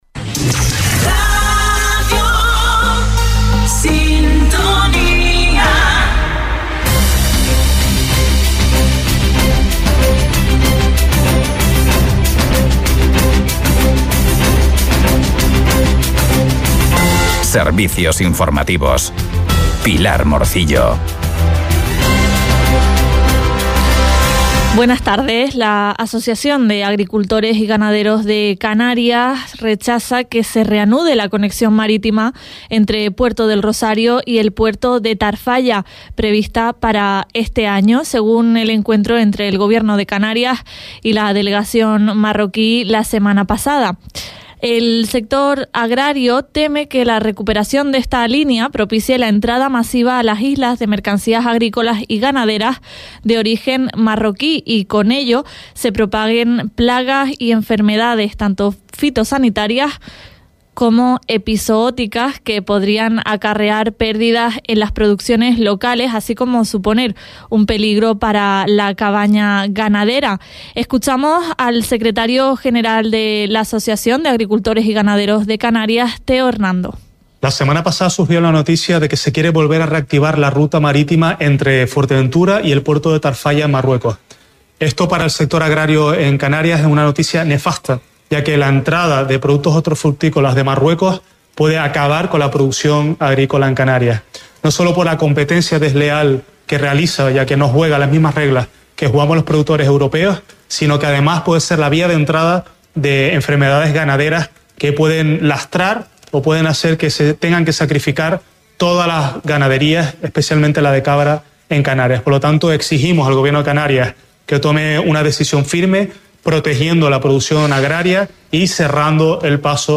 En Radio Sintonía Fuerteventura les mantenemos al día de la actualidad local y regional en nuestros informativos diarios a las 9.30 y 13.15 horas. Por espacio de 15 minutos acercamos a la audiencia lo más destacado de los distintos municipios de la isla, sin perder la atención en las noticias regionales de interés general.
Servicios Informativos